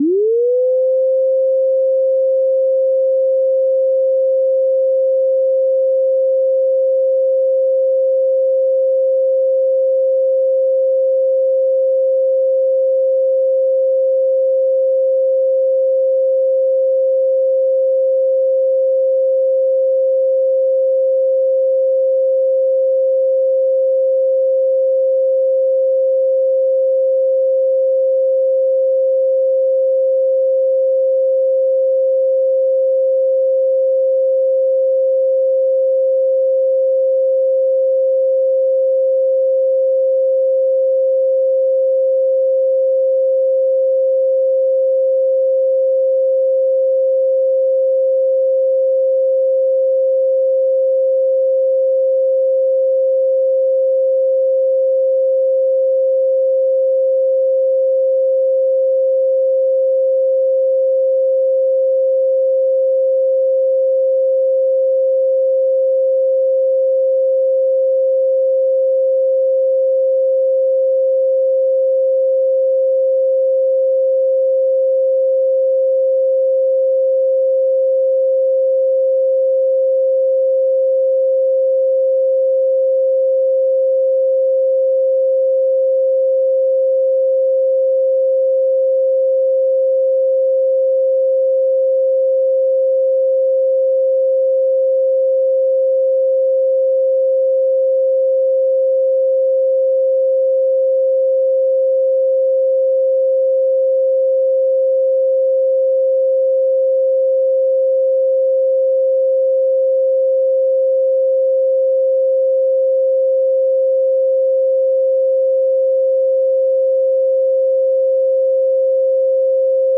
528 Hz Tone Sound Solfeggio Frequency
Solfeggio Frequencies